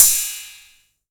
808CY_8_TapeSat.wav